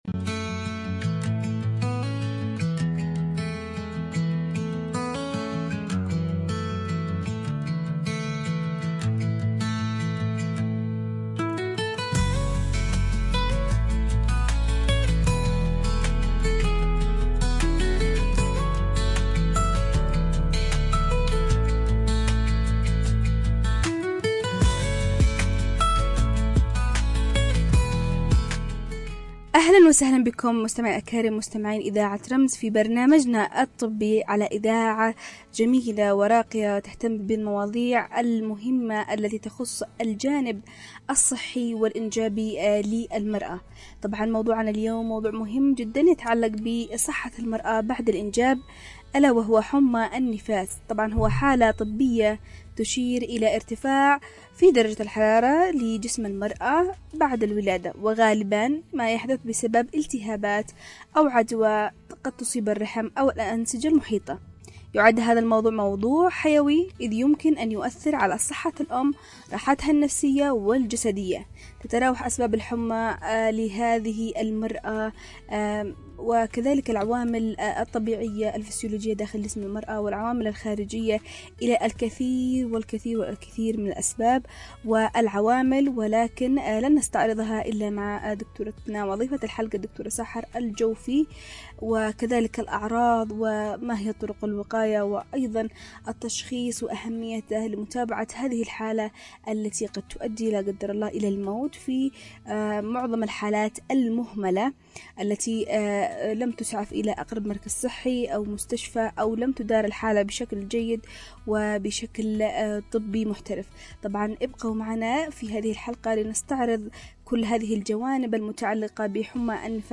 🔍 عنوان الحلقة: "نزيف ما بعد الولادة" 📅 الموعد: يوم الأربعاء ⏰ الساعة: 01:00 ظهراً 📻 عبر أثير إذاعة رمز 🎧 رابط البث المباشر: